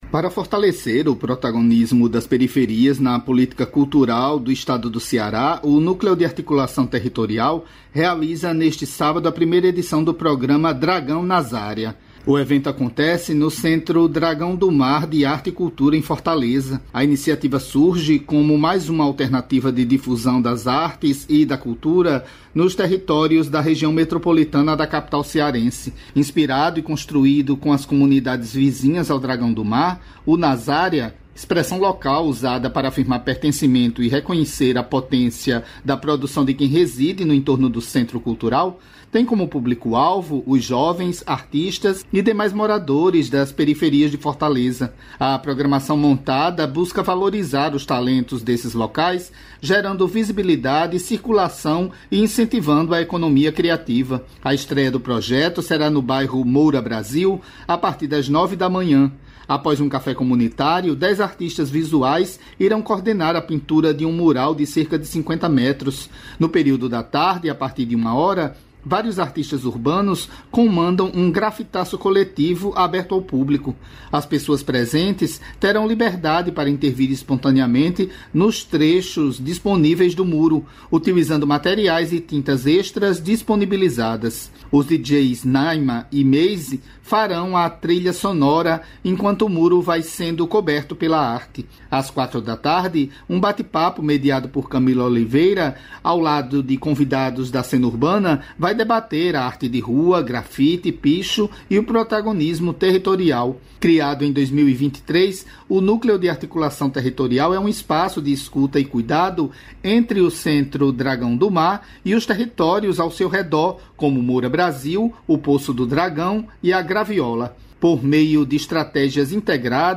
Essa voz que dá início ao áudio da matéria é do robô Otto, ele está sendo desenvolvido por professoras da Universidade Federal de Mato Grosso, para auxiliar nas sessões de terapia de crianças com TEA - Transtorno de Espectro Autista.